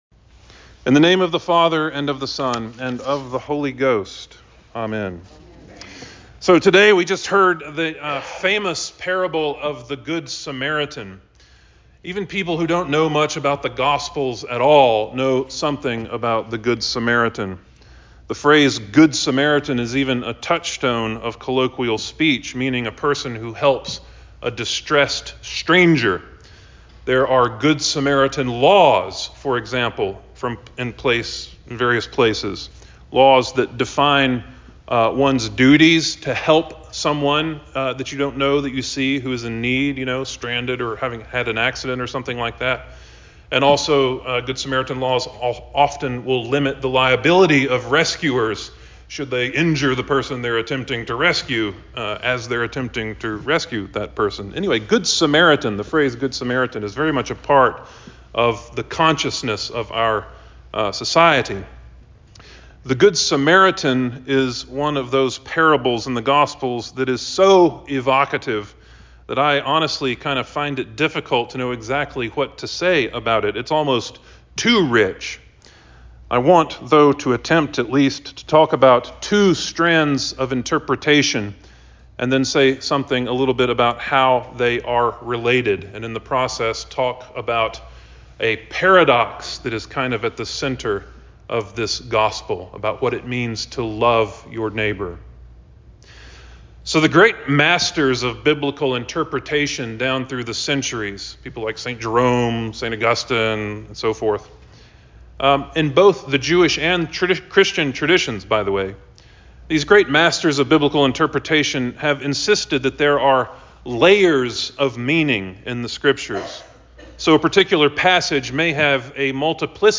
Trinity XIII Sermon 09.03.23